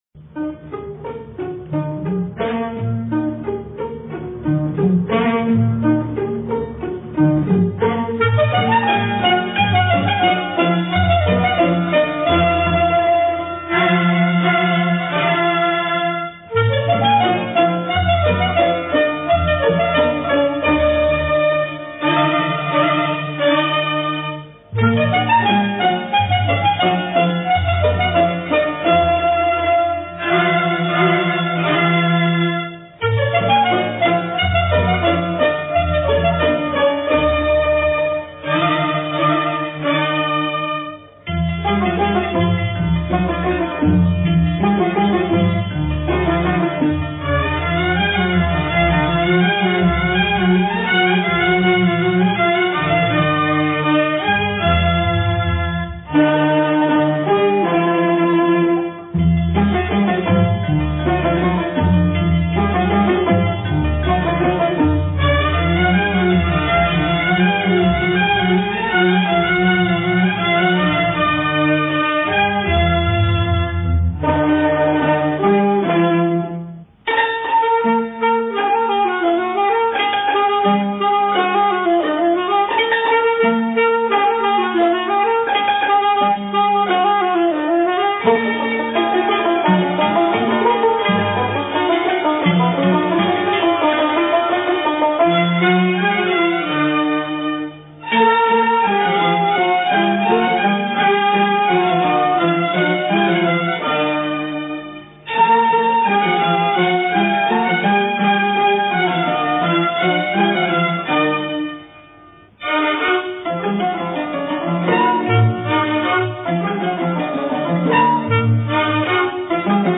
مایه: اصفهان